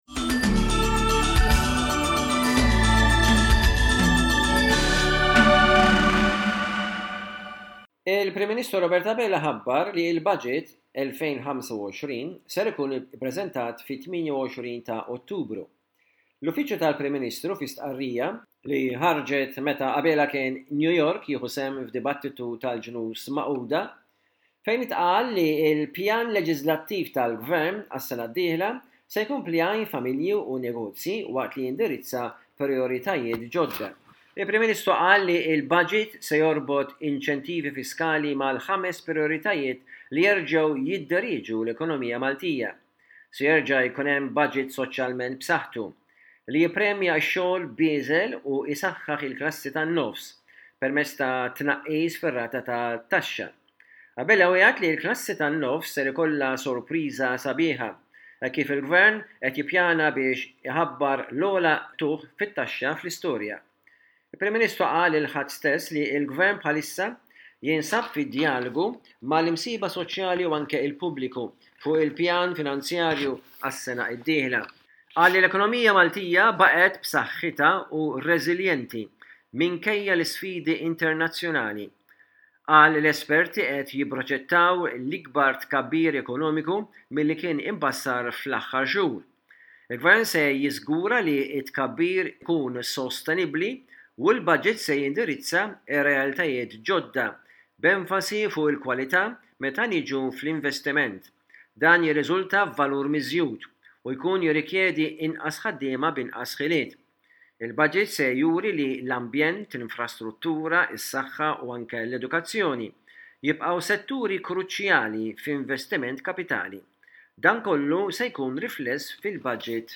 Aħbarijiet minn Malta: 04.10.24